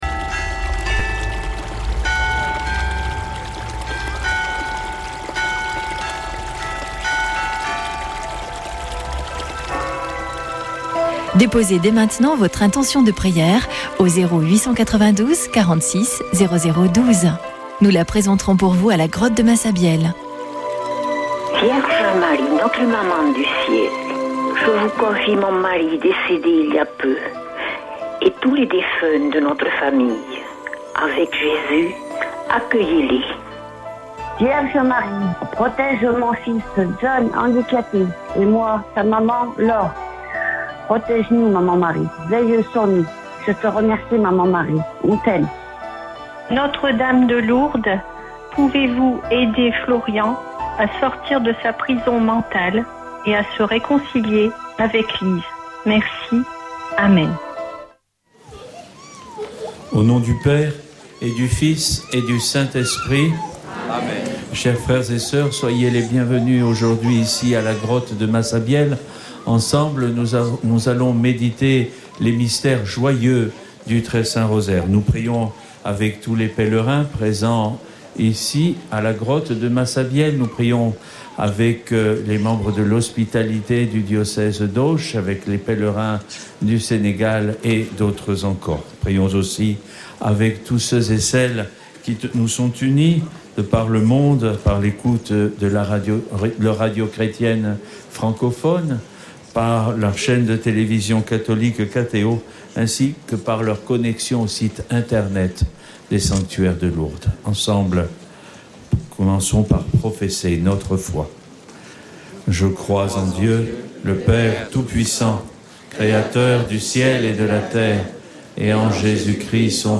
Chapelet de Lourdes du 16 août
Une émission présentée par Chapelains de Lourdes